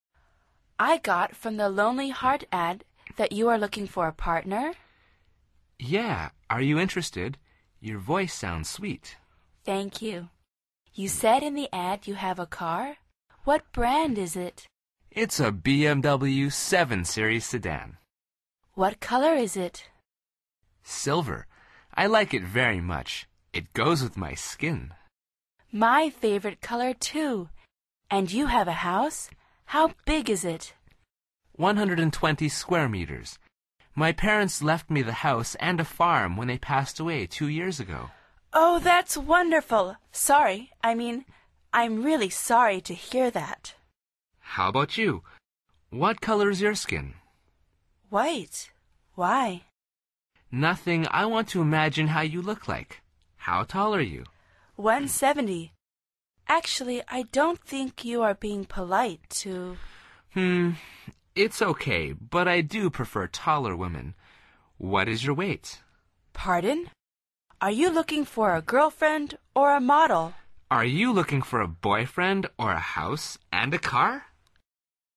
Pulsa las flechas de reproducción para escuchar el segundo diálogo de esta lección. Al final repite el diálogo en voz alta tratando de imitar la entonación de los locutores.